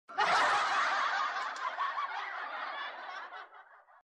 ngakak xox lirih Meme Sound Effect
ngakak xox lirih.mp3